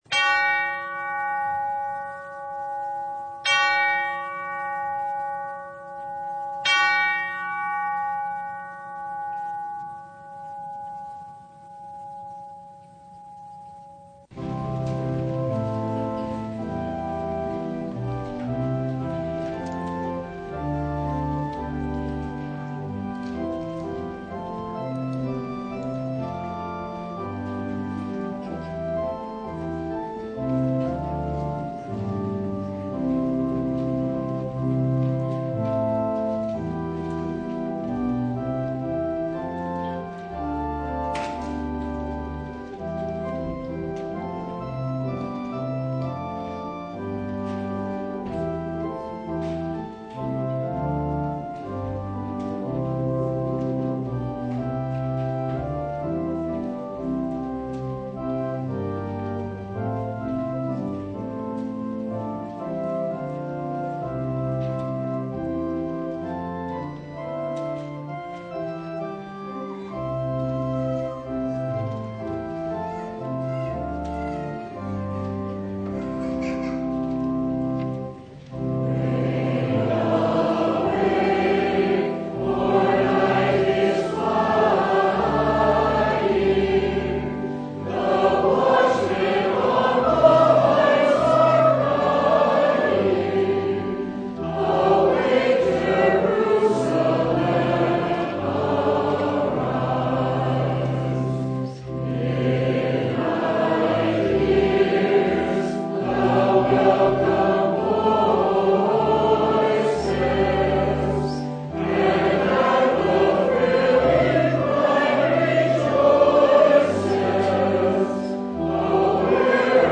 Matthew 25:1-13 Service Type: Sunday Jesus shall come again as the Bridegroom.